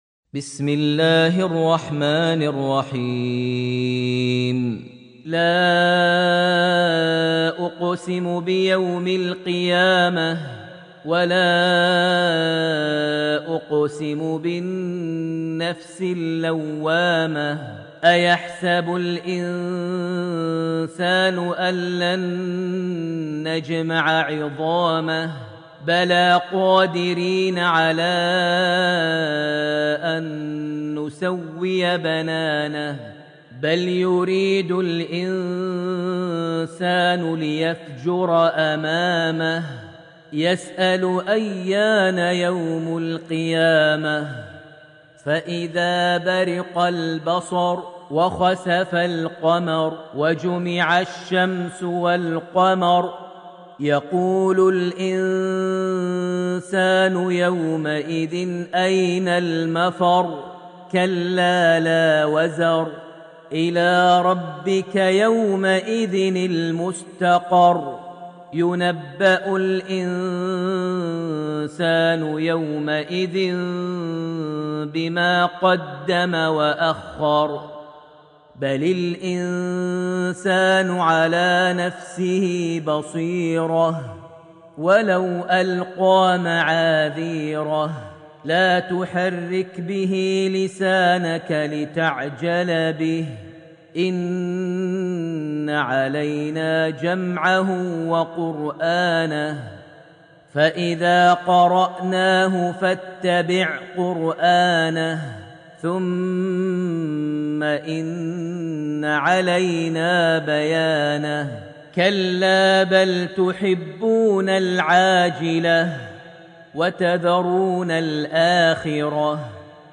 Surat Al-Qiyama > Almushaf > Mushaf - Maher Almuaiqly Recitations